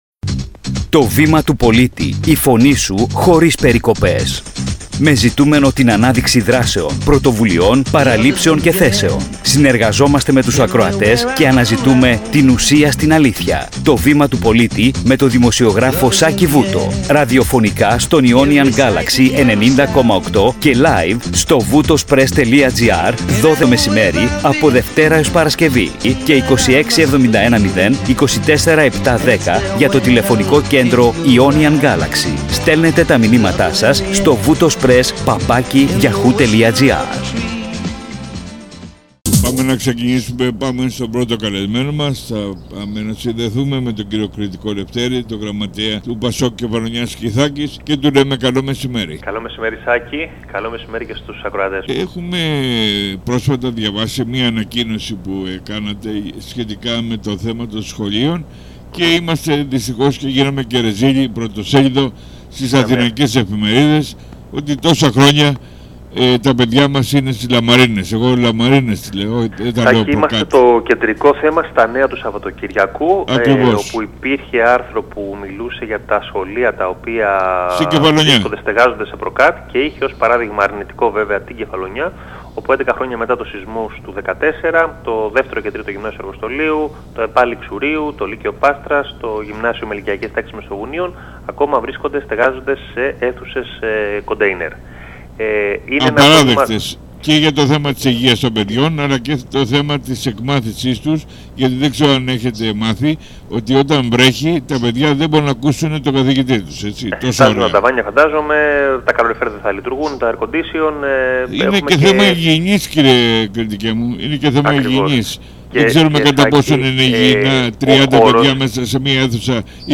Σύντομη περίληψη συνέντευξης